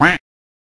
BadHitSound_coin.ogg